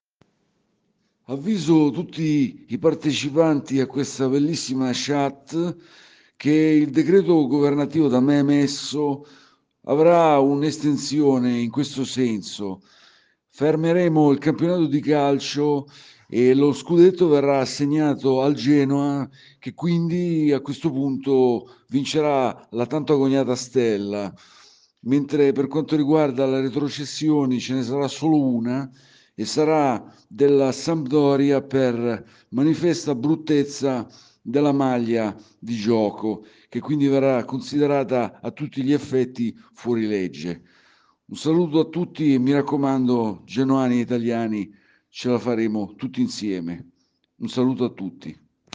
magnifico genoano e imitatore!